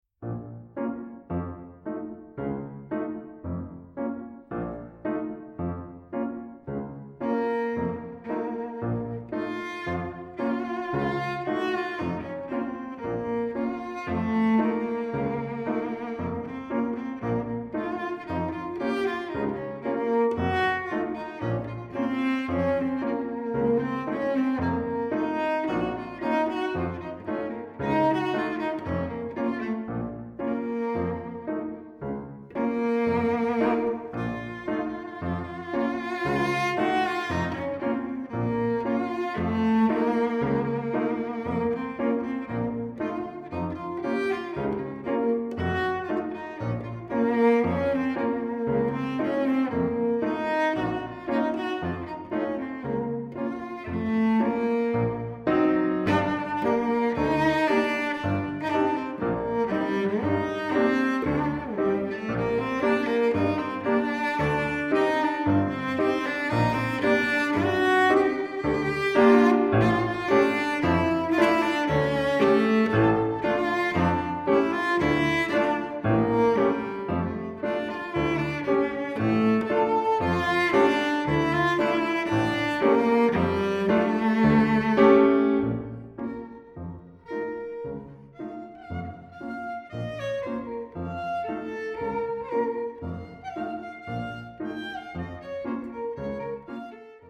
Australian classical music
Sonata for violoncello and piano